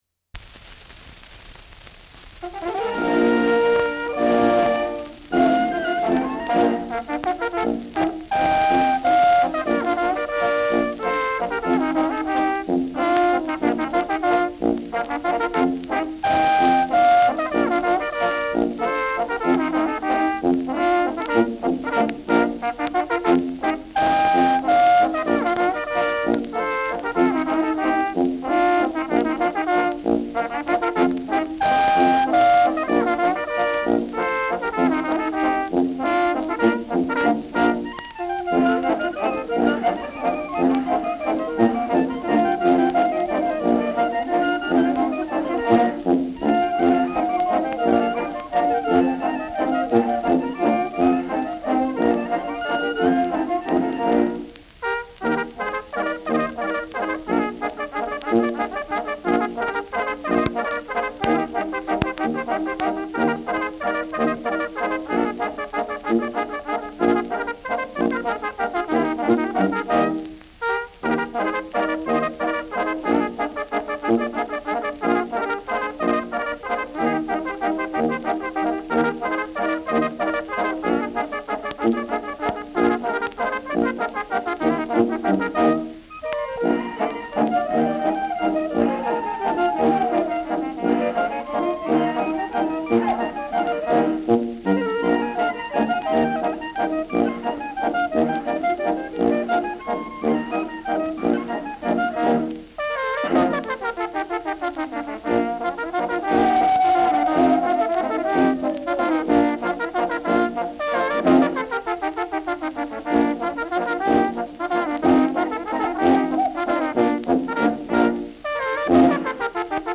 Schellackplatte
Mit Juchzern.